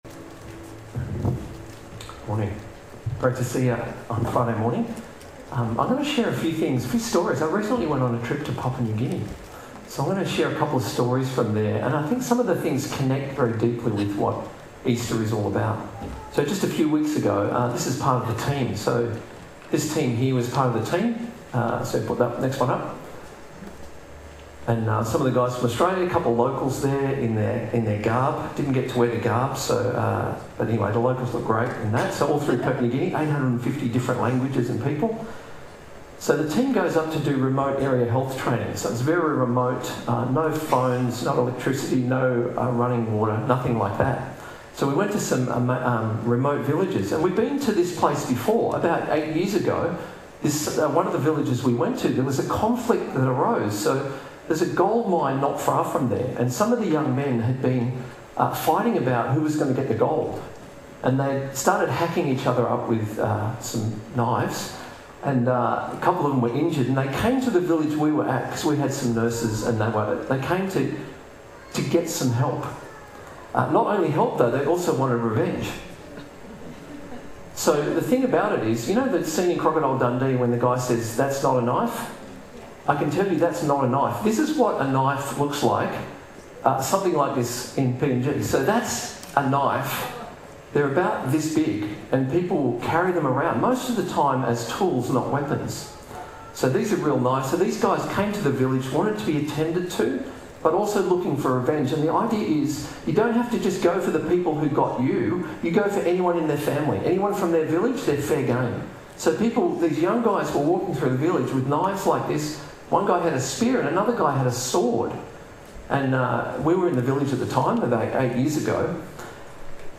Good Friday Message.